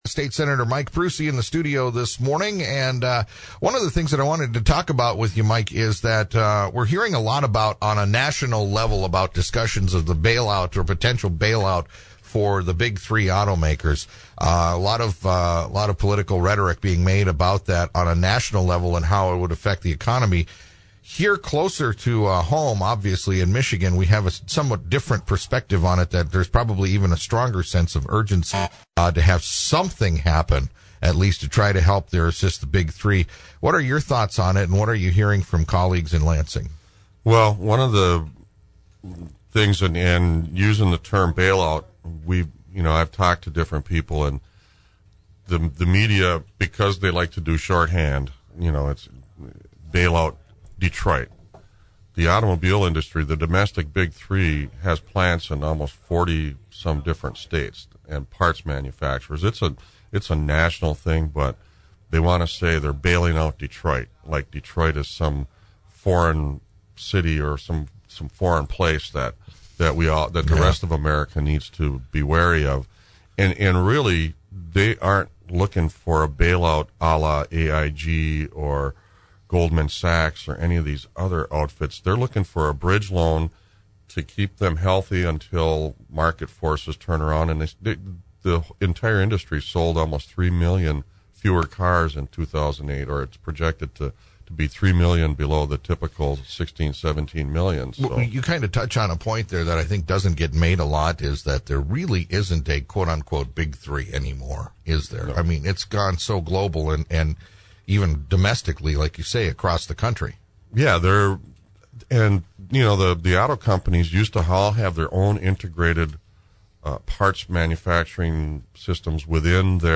PRUSIInterview with the new Minority Leader in the State House in Lansing about the previous year's activities in the Legislature.